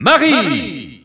Announcer pronouncing Isabelle's name in French.
Isabelle_French_Announcer_SSBU.wav